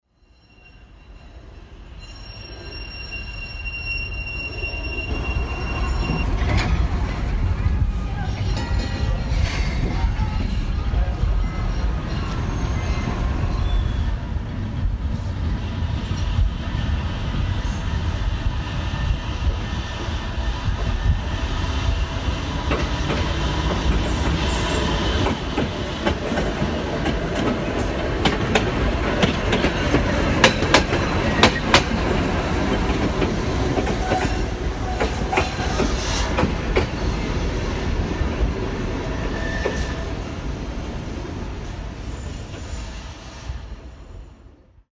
«Panorama Sonore du Québec» est une complitation de courts intermèdes d'une durée de 60 secondes qui présentent les sons ambients du Québec (sons de la nature, événements historiques, lieux touristiques, lieux de travail, ...) diffusés régulièrement tout au long de la journée sur les ondes de Radio Québec International (RQI1: Première Chaîne).
(Tramway 1959 à l'Exporail)
tramway.mp3